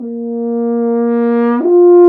Index of /90_sSampleCDs/Roland L-CD702/VOL-2/BRS_F.Horn FX/BRS_Intervals
BRS F.HRN 0D.wav